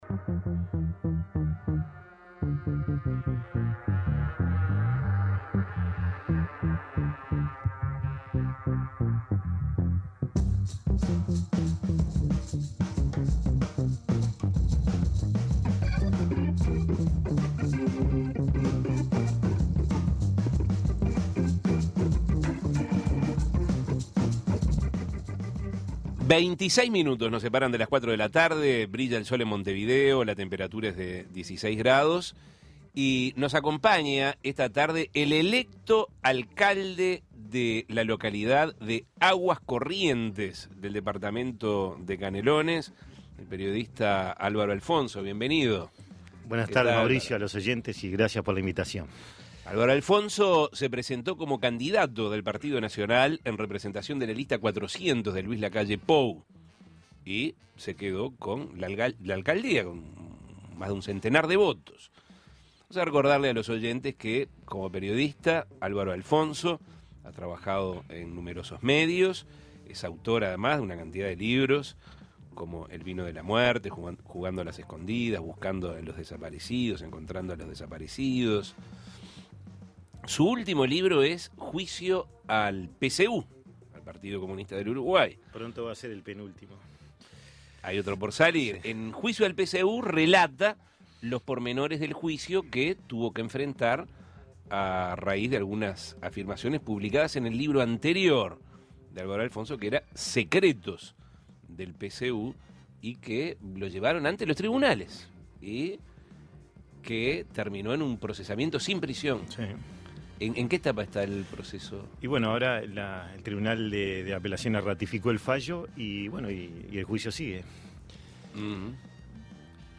Se presentó como candidato del Partido Nacional, en representación de la lista 400 de Luis Lacalle Pou, y 101 votos le bastaron para quedarse con la Alcaldía de la localidad de Aguas Corrientes. Escuche la entrevista.